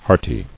[heart·y]